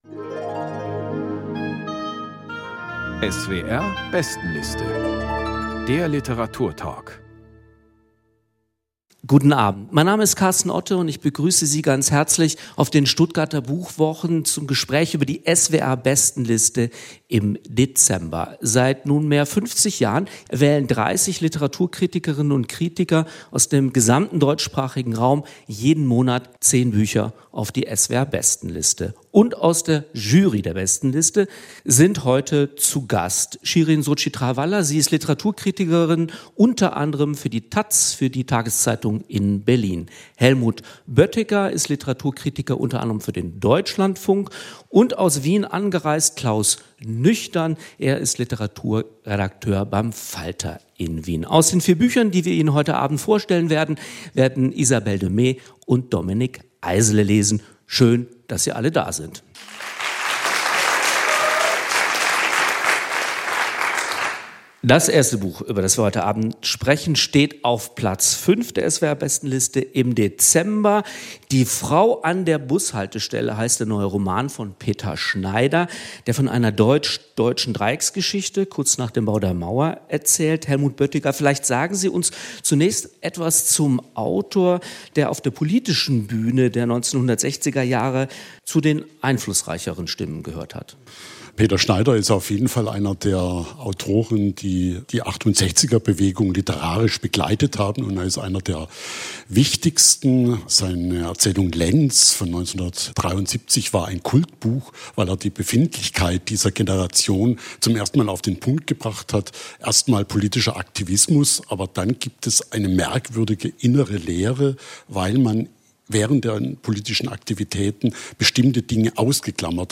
Peter Schneider: Die Frau an der Bushaltestelle | Lesung und Diskussion ~ SWR Kultur lesenswert - Literatur Podcast